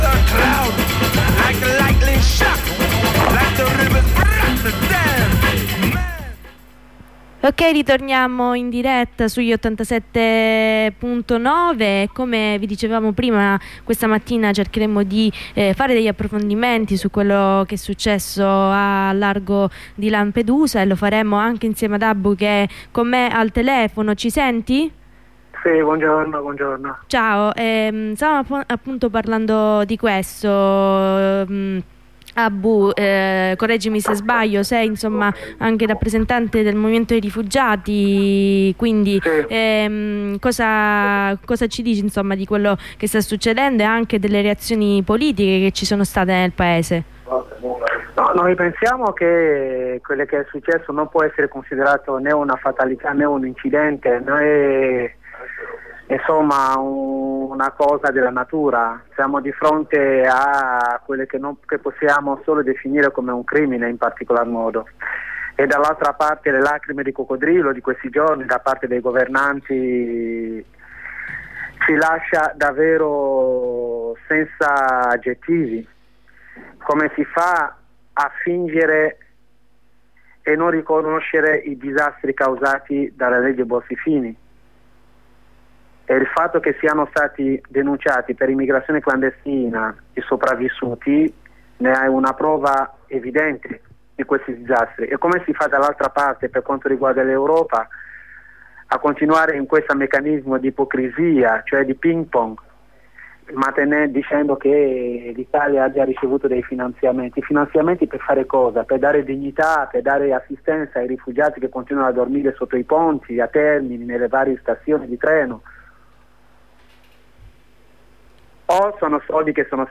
Corrispondenza con un compagno di garage anarchico di Pisa